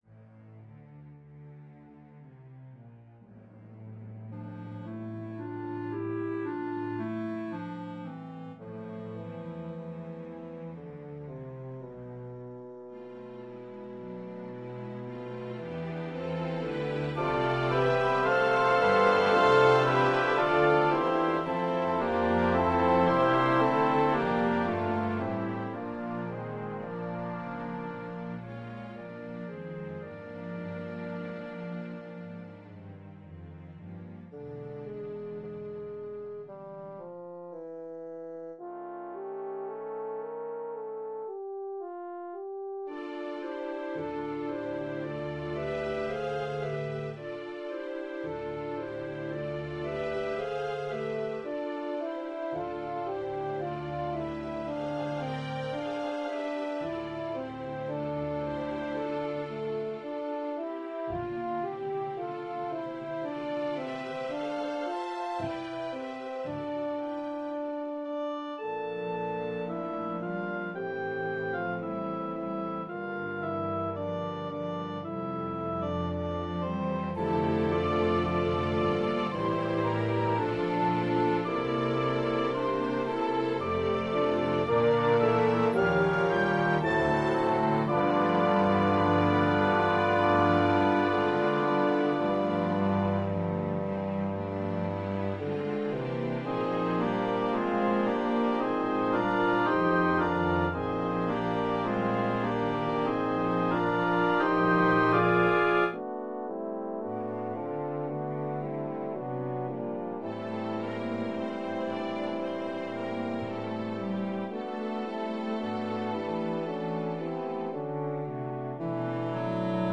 Traditional Carol